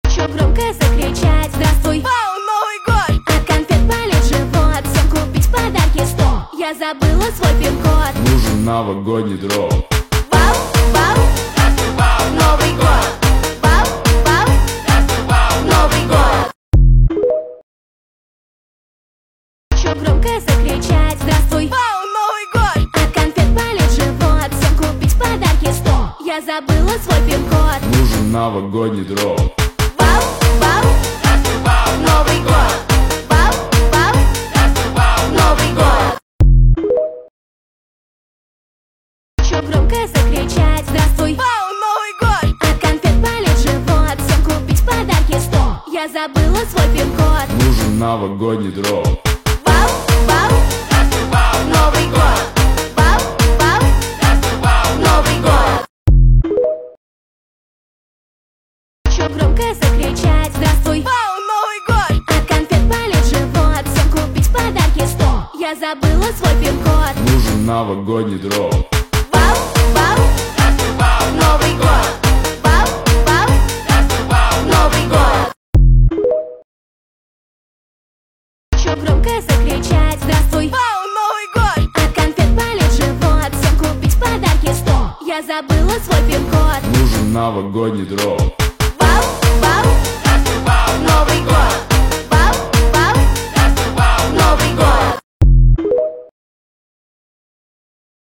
Новогодняя песня